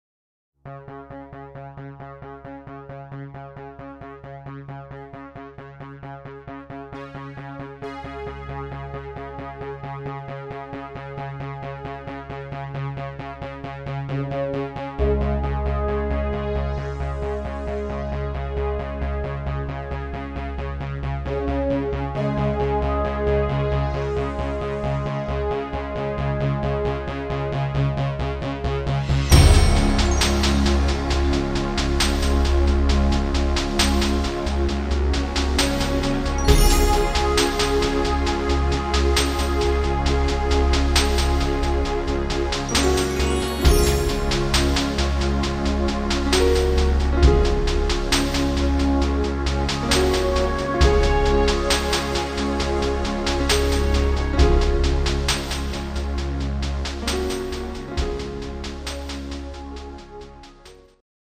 instrumental Synthesizer
Rhythmus  Slow
Art  Instrumental Allerlei